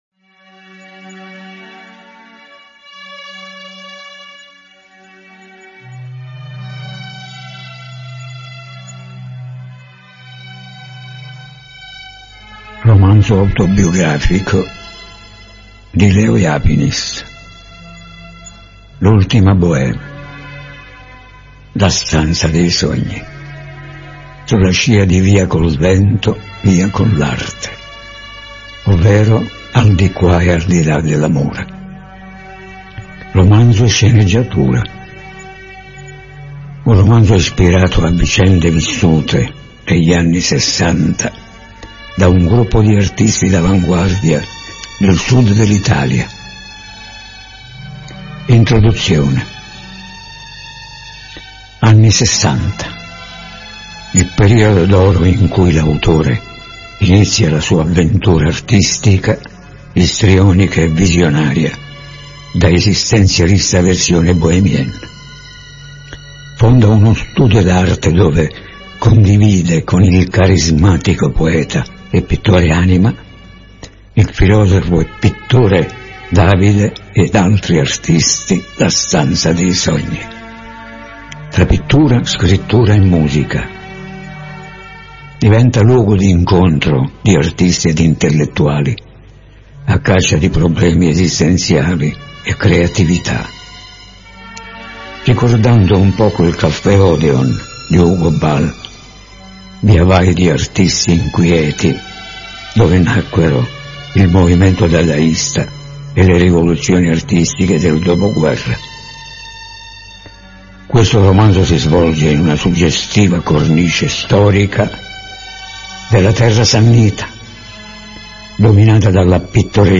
AUDIO BOOK